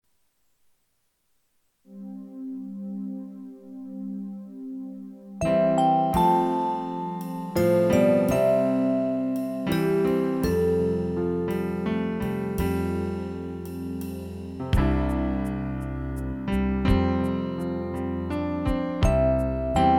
Playback zur Begleitung
(ohne Gesang)